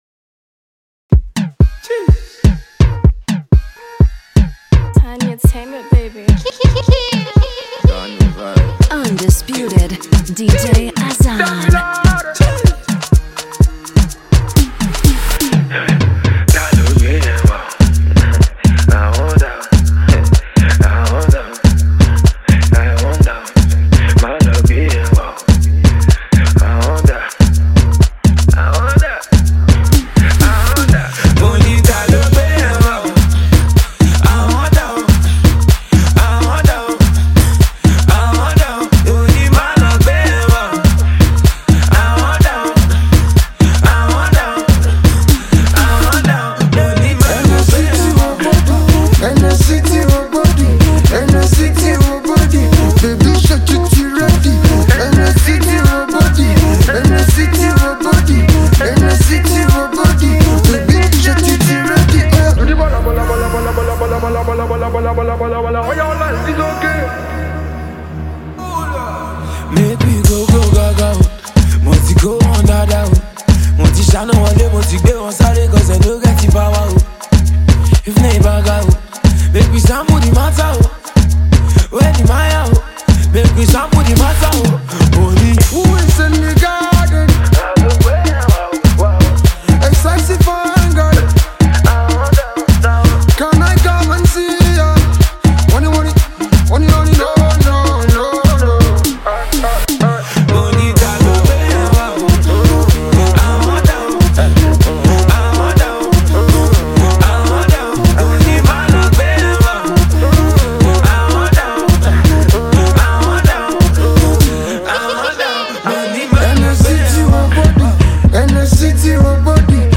Multi Talented Nigeria indigenous disc jockey and singer
Street/Party song
high-life music